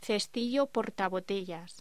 Locución: Cestillo portabotellas